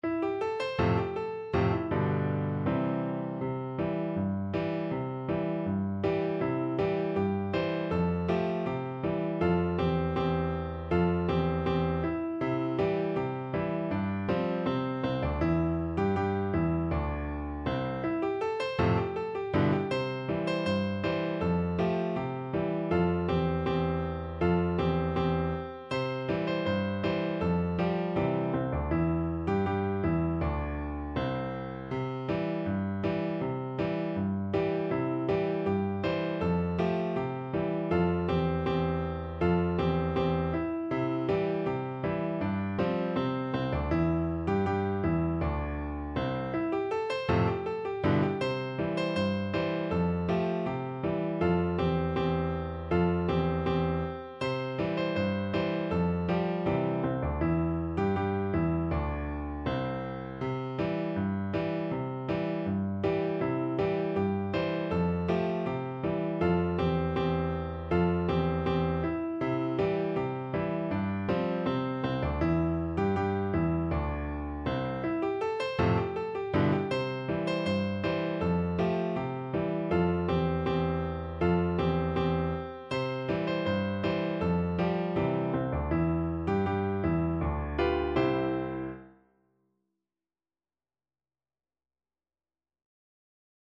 Piano version
Key: C major
Time Signature: 2/2
Tempo Marking: With gusto
Instrument: Piano